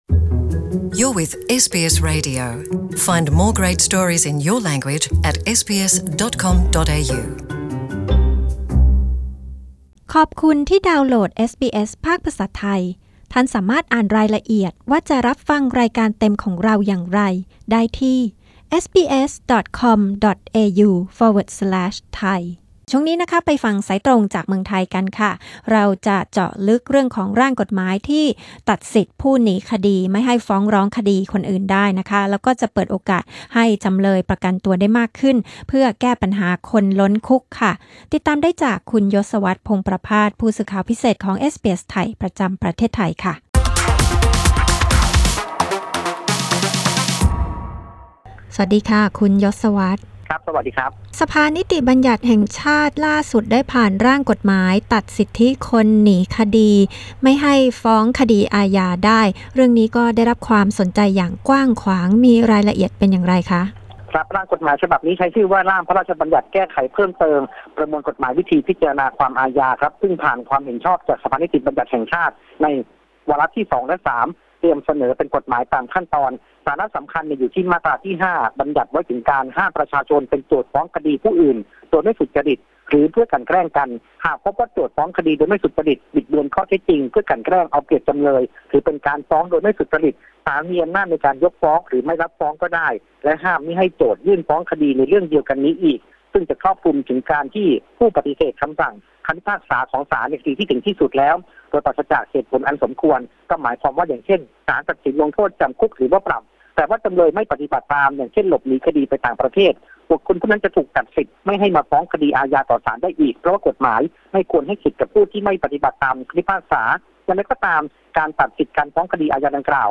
รายงานสายตรงจากเมืองไทย โดยเอสบีเอส ไทย Source: Pixabay